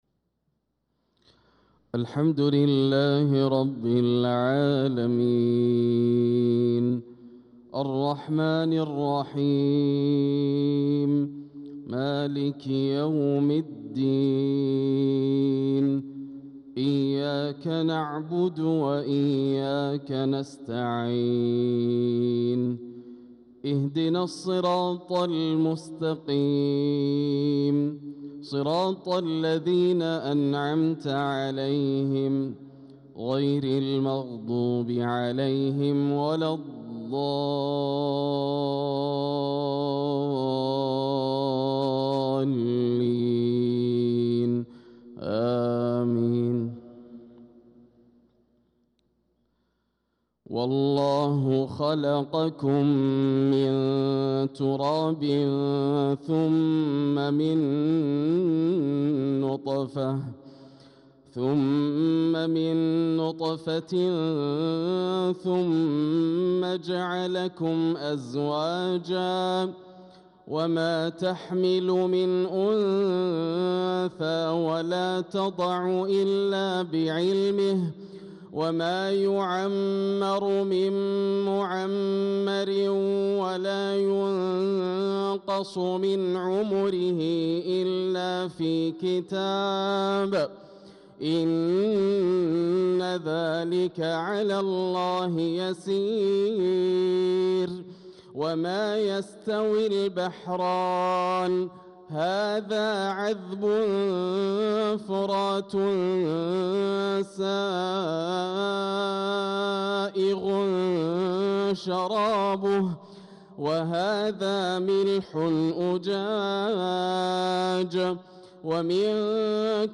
صلاة الفجر للقارئ ياسر الدوسري 16 ذو الحجة 1445 هـ
تِلَاوَات الْحَرَمَيْن .